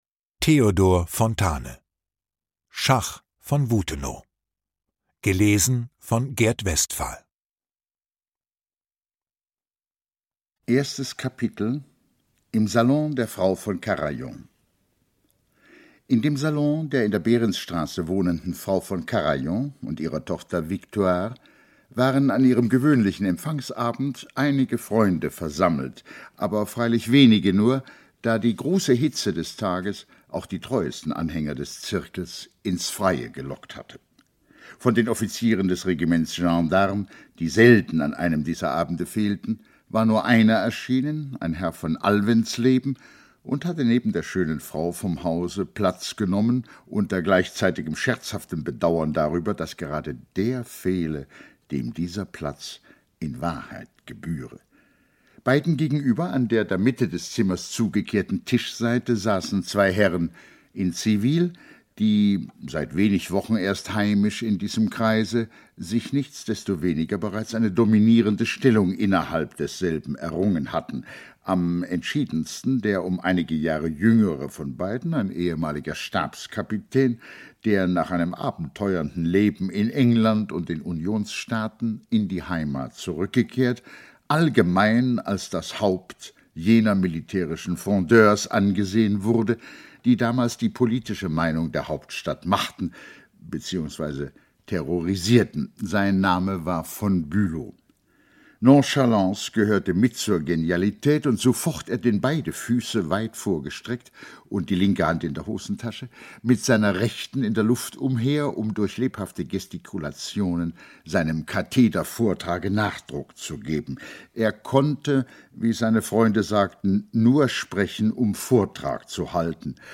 Ungekürzte Lesung mit Gert Westphal (1 mp3-CD)
Gert Westphal (Sprecher)